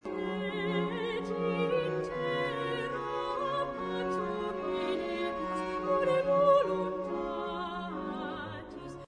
合唱のパートはそれぞれ一人ずつで歌うべきだ